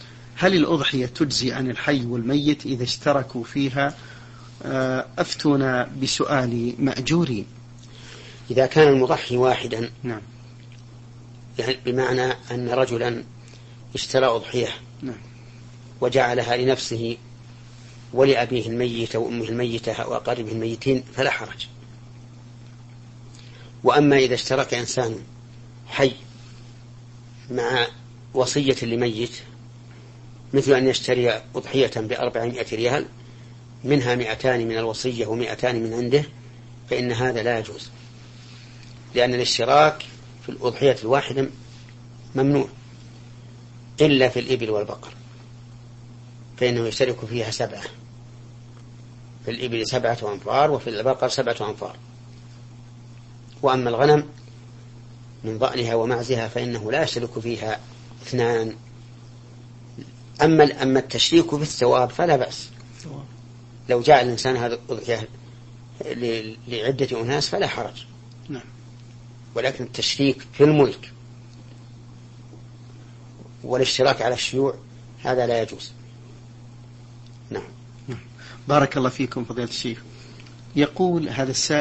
محمد بن صالح العثيمين رحمه الله